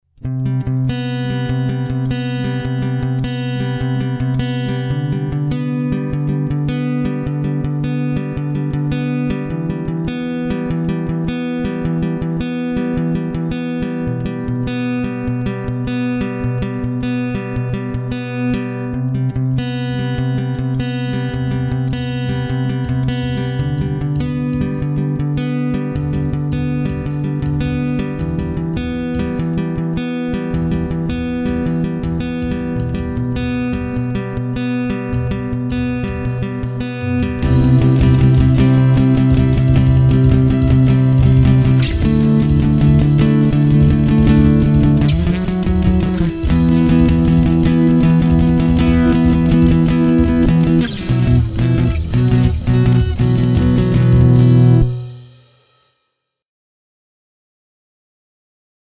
Вниз  Играем на гитаре
Что-то ты не заодно с барабанами играл :-D